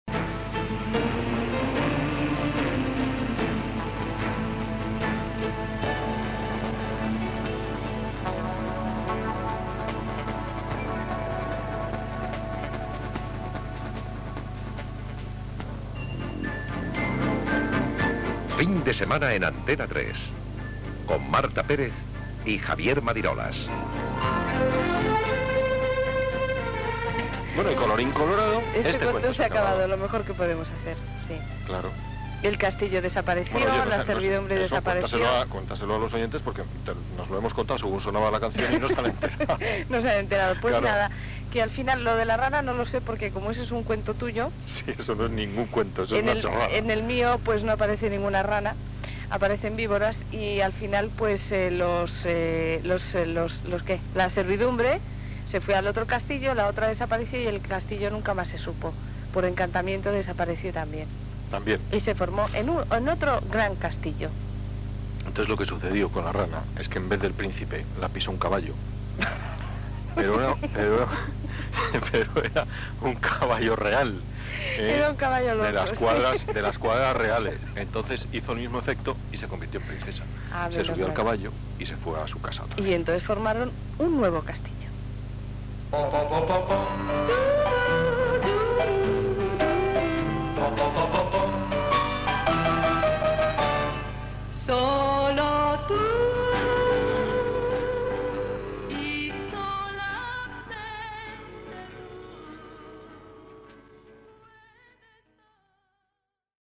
Música, indicatiu del programa i comentari sobre el final d'un conte, que simbolitza l'absorció d'Antena 3 radio per part del Grupo Prisa.
Fragment de la darrera emissió d'Antena 3 radio del 18 de juny de 1994.